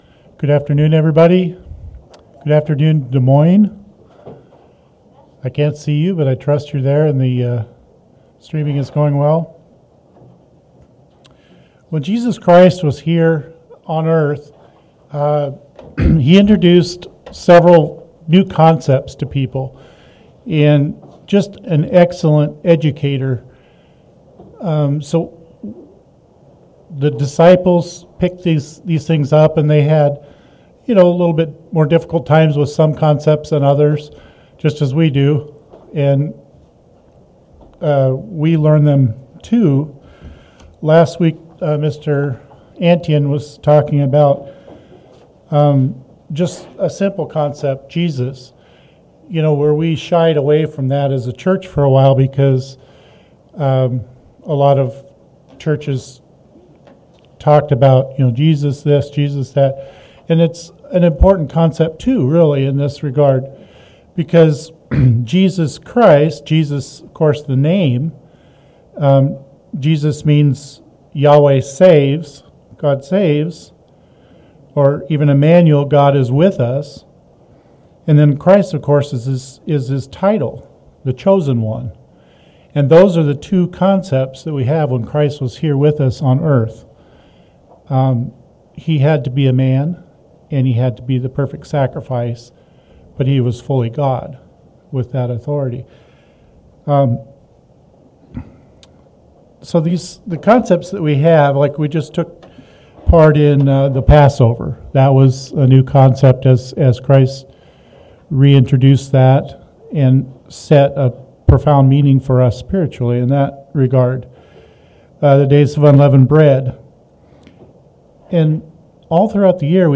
Sermons
Given in Omaha, NE